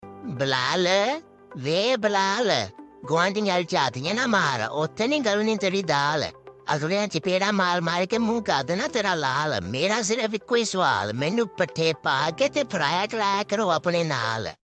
Goat Calling Bilal Name Funny Sound Effects Free Download